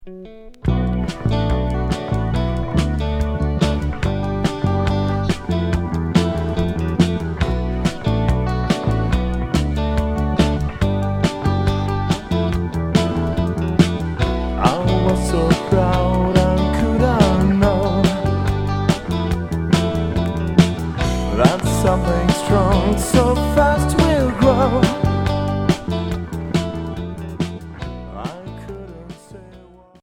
Cold wave Unique 45t